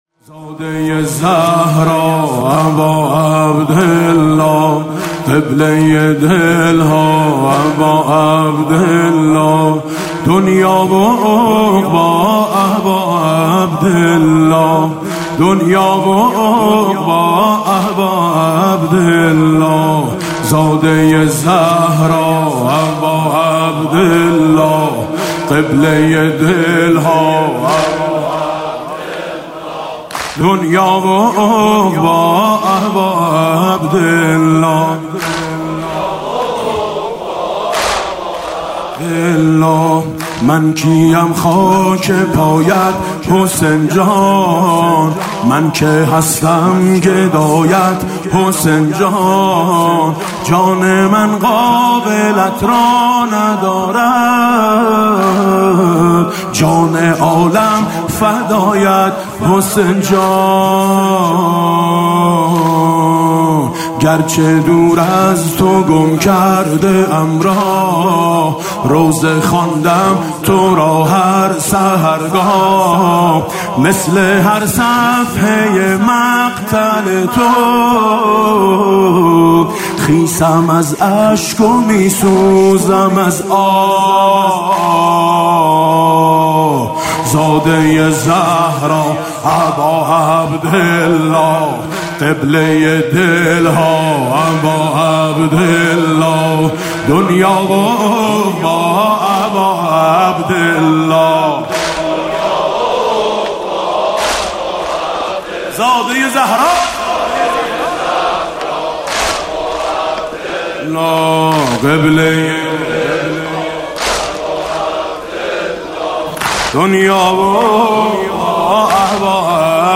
مداحی جدید میثم مطیعی شب ششم محرم 1399هیئت میثاق با شهدا